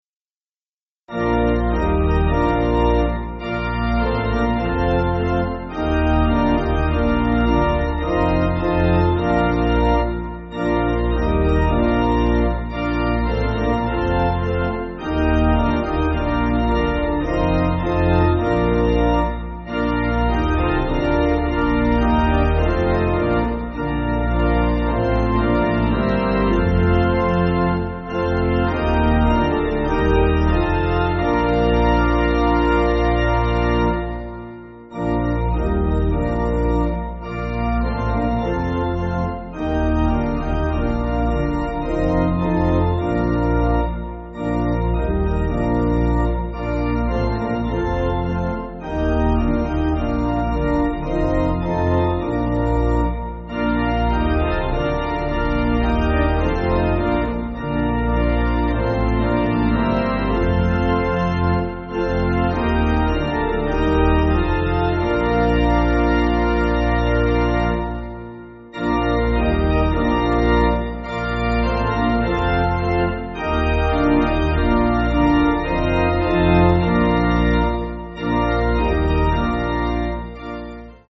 Organ
(CM)   3/Bb